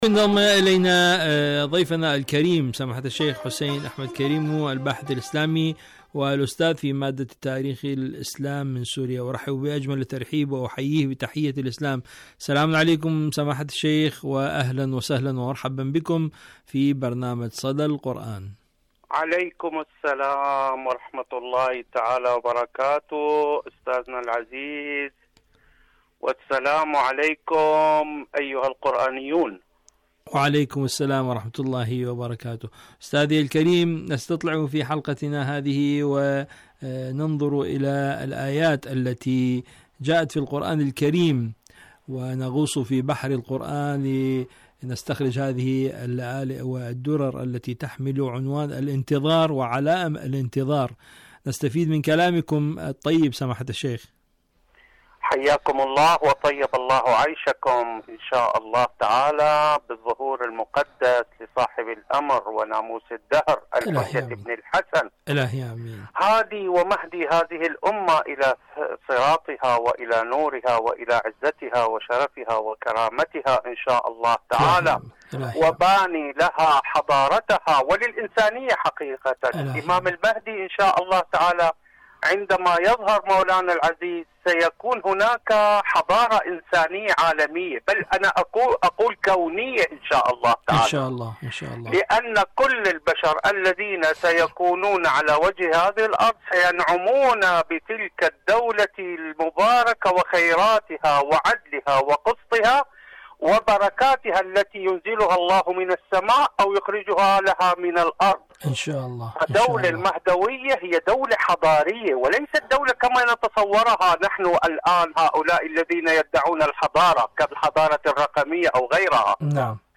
إذاعة طهران- صدى القرآن: مقابلة إذاعية